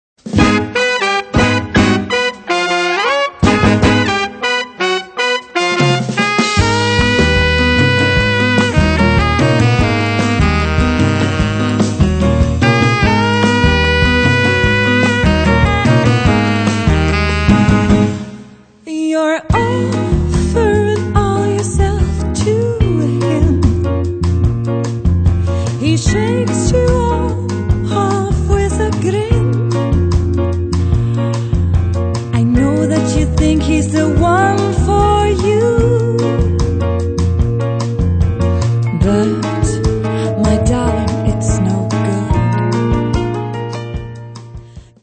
ist ein zeitloser Clubsound